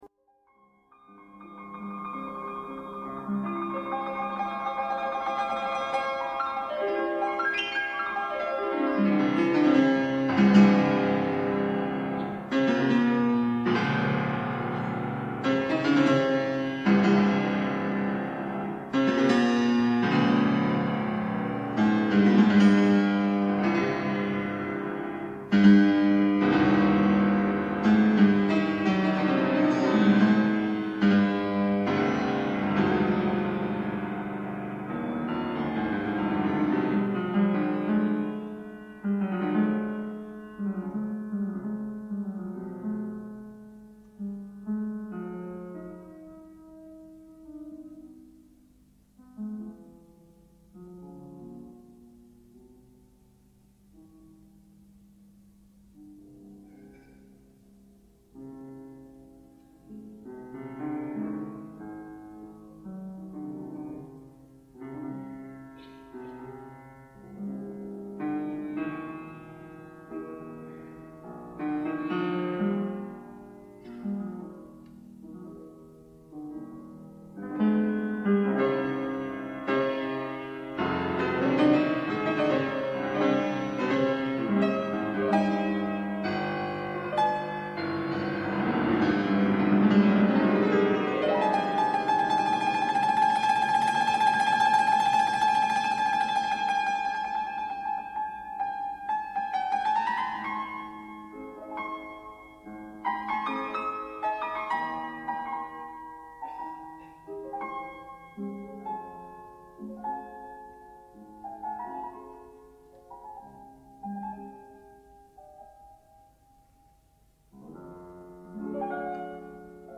Recorded live in concert
legendary Australian pianist
Classical